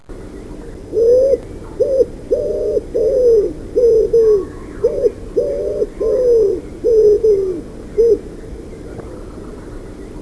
Colombaccio.wav